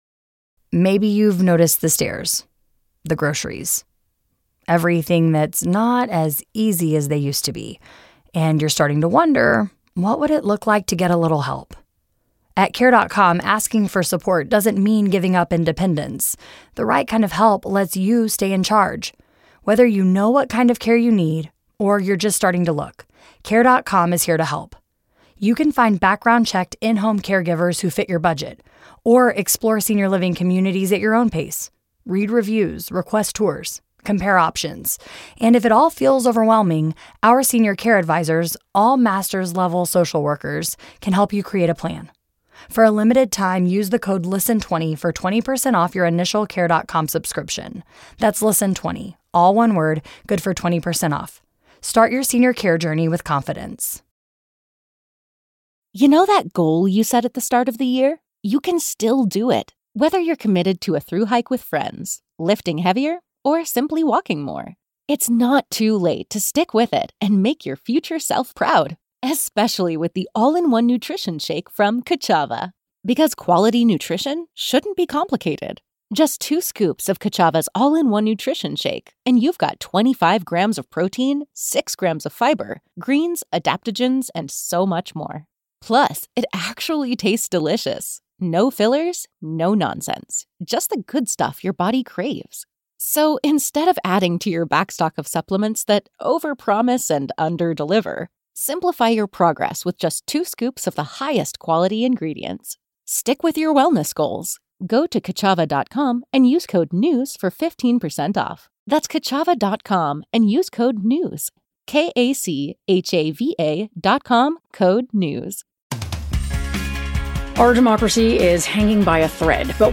Two prominent Iranian-American journalists, Jason Rezaian of the Washington Post and Hooman Majd of NBC News, say the regime may be losing its grip.